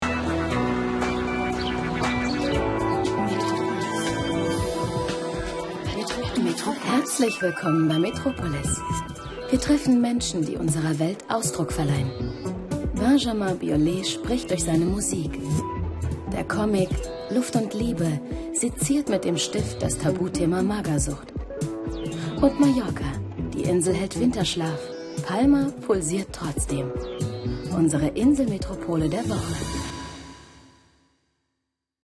Warme,sinnliche,frische,femine aber auch markante Stimme, deutsch für Funk und TV Werbung, Synchron, Hörbücher, CD-Rom, Hörspiele, Voice Over, Imagefilme, Doku, Moderation etc
Kein Dialekt
Sprechprobe: Sonstiges (Muttersprache):